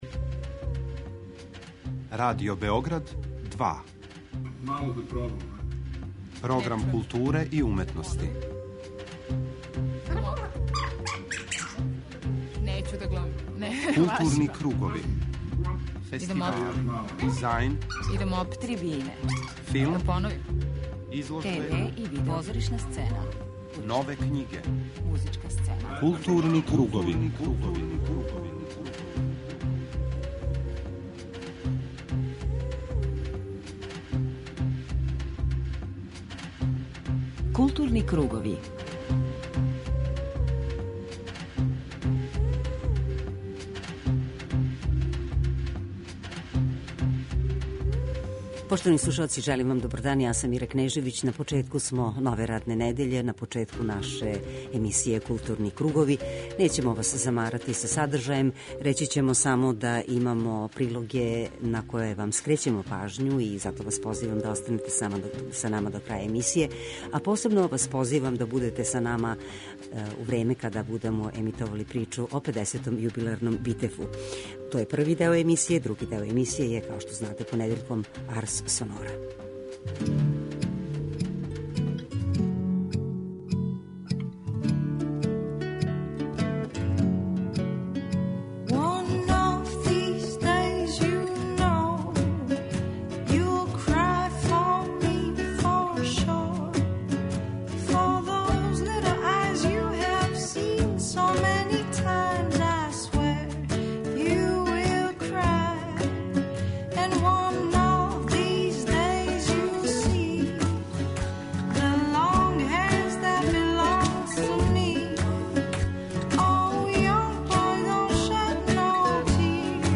преузми : 40.28 MB Културни кругови Autor: Група аутора Централна културно-уметничка емисија Радио Београда 2.